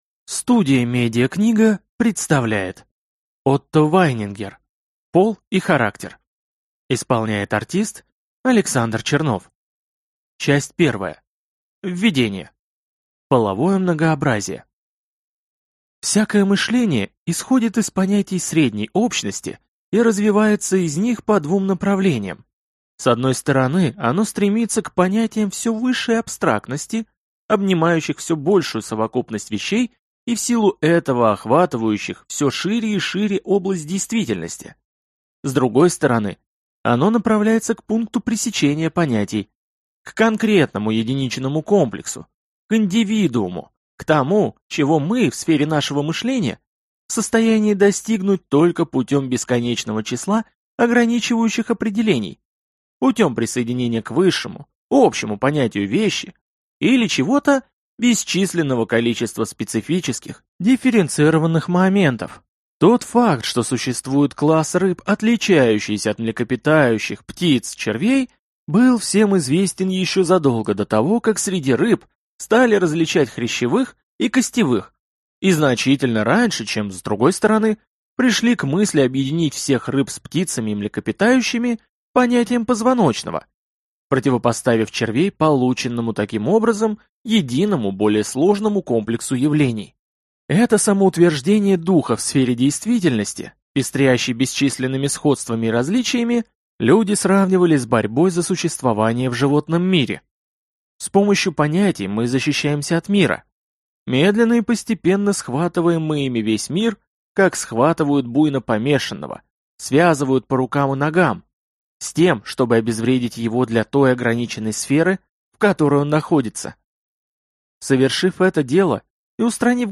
Аудиокнига Пол и характер. Выпуск 1 | Библиотека аудиокниг